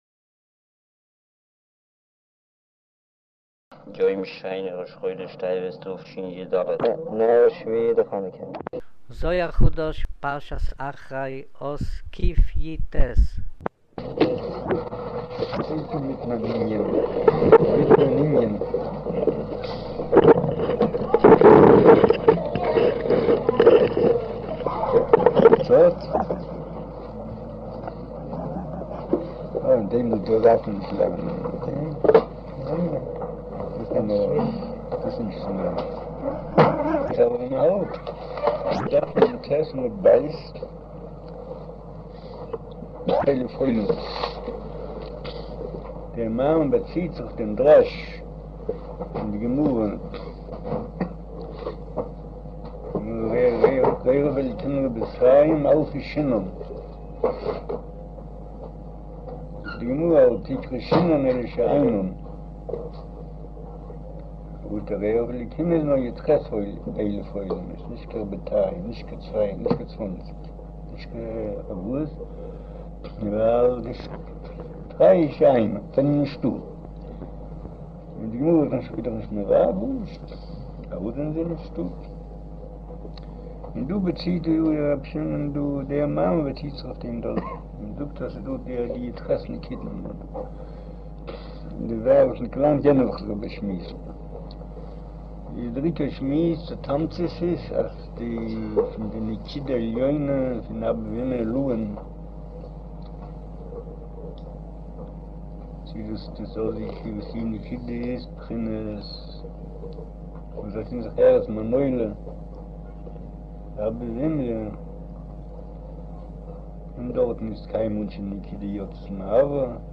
אודיו - שיעור מבעל הסולם זהר חדש, אחרי, אות קיט' - קכה'